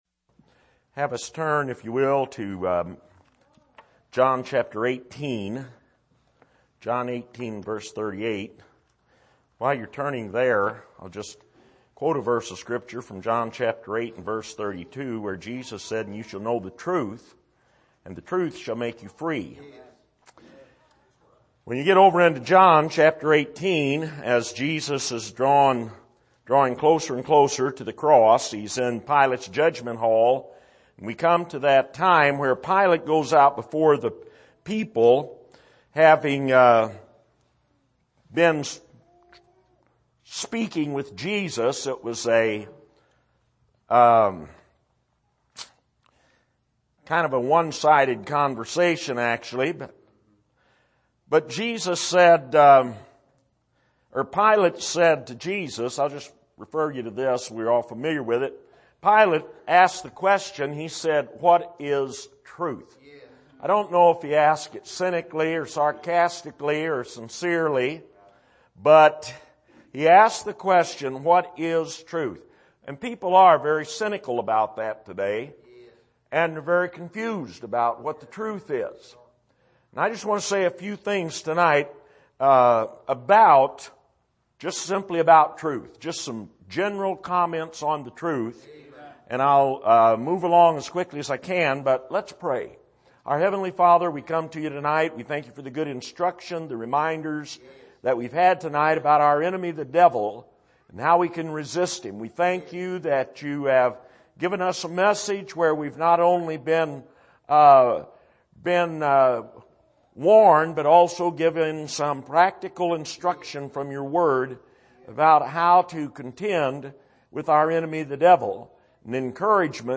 Service: Bible Conference